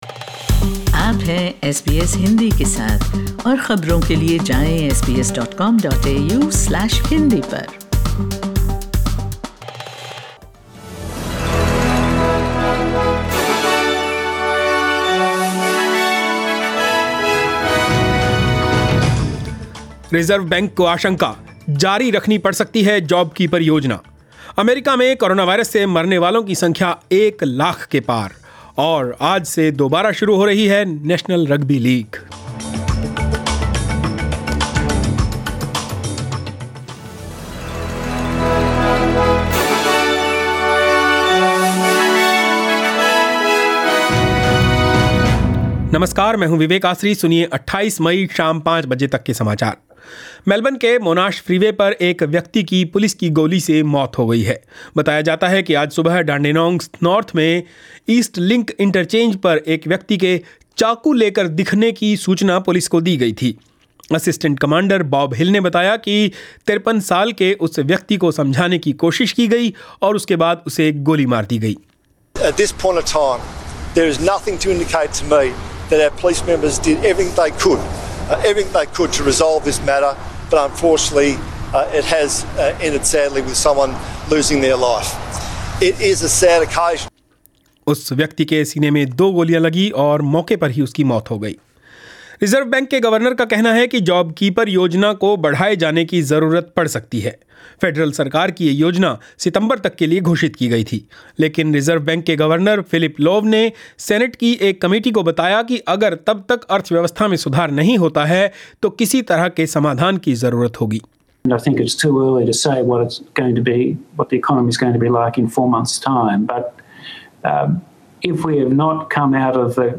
In this bulletin: US crosses a 'terrible threshold' as coronavirus death toll passes 100,000. Over 6,000 Coronavirus cases in India for 7th straight day, and NRL resumes today...